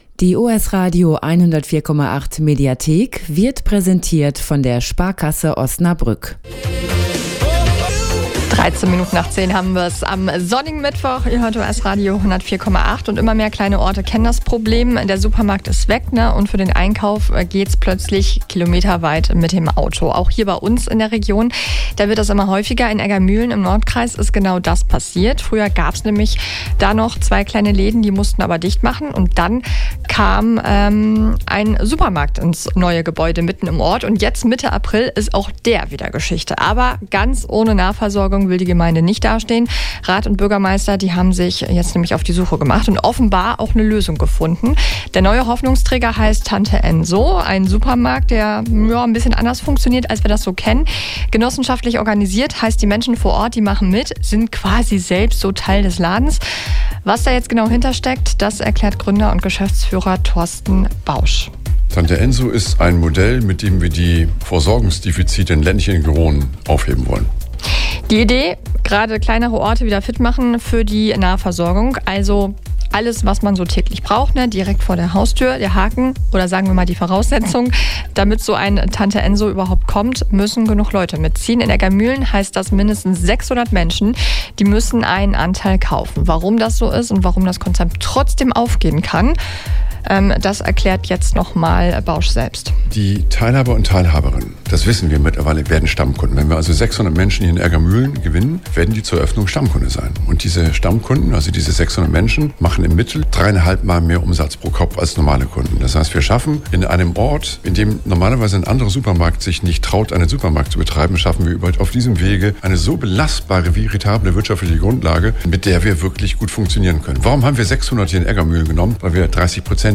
Noch ist der neue Laden aber nicht sicher: Mindestens 600 Menschen müssen Anteile zeichnen. Warum das Konzept trotzdem gute Chancen hat und welche Vorteile es bietet – das hört ihr im Mitschnitt.